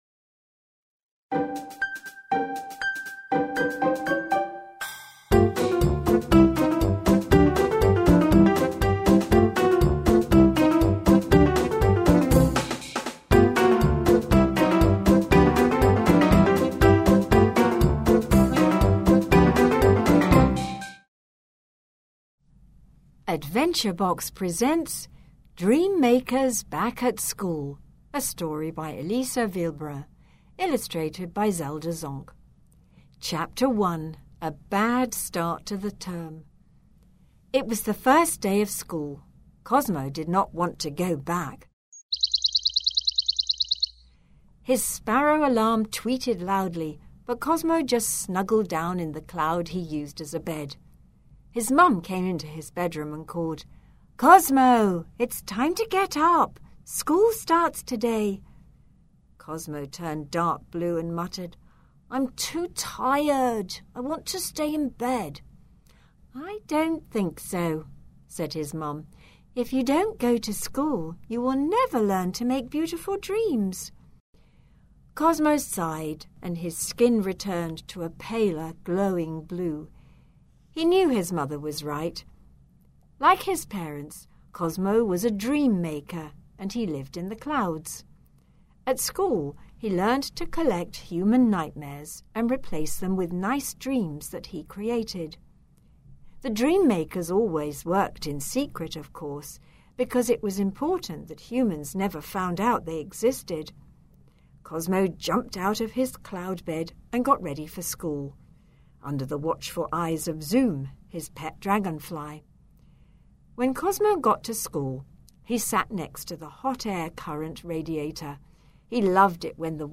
The recording by professional actors brings the stories to life while helping with the comprehension and the pronunciation.